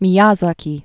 (mē-yäzä-kē, mēyä-zäkē)